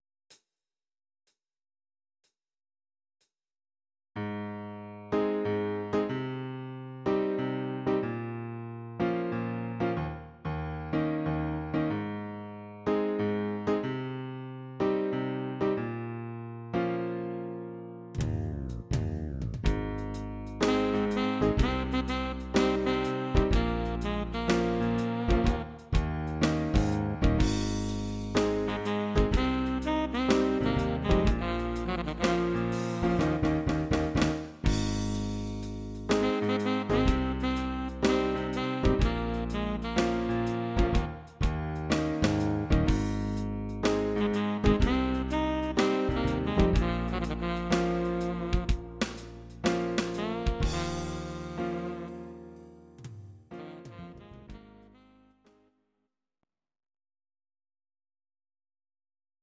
Audio Recordings based on Midi-files
Pop, Rock, 1990s